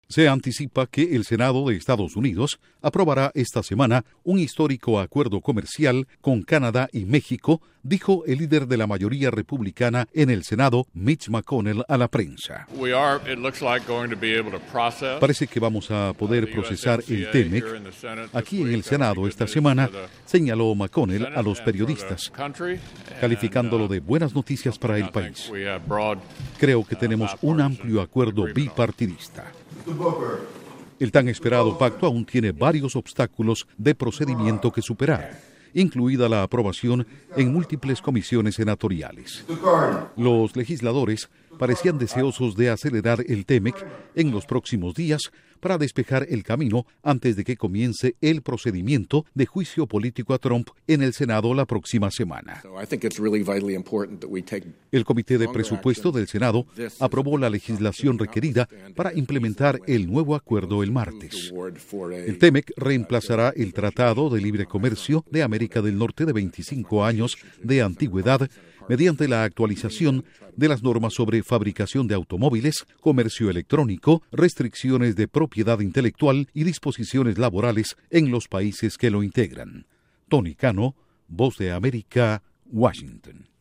Duración: 1:23 Con declaraciones de líder de mayoría republicana del Senado/ Mitch McConnell y de senadores demócratas